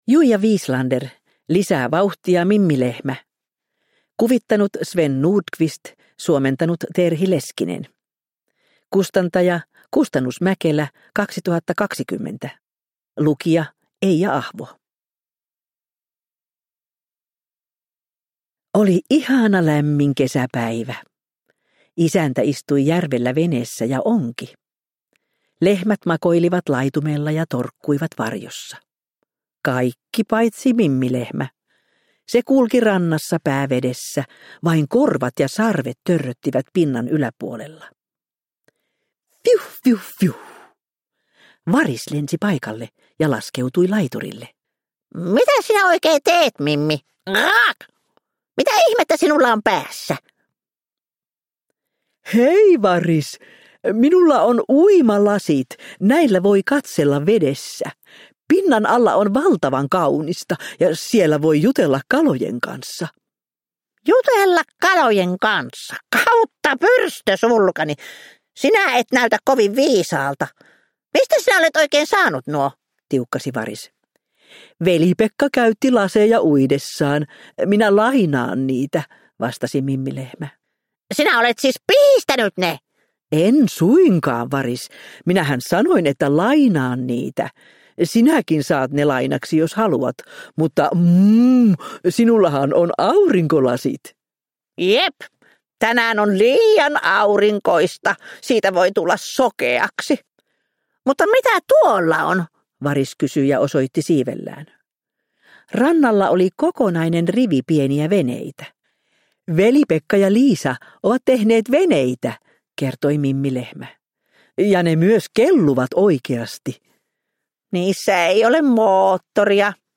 Lisää vauhtia, Mimmi Lehmä! – Ljudbok – Laddas ner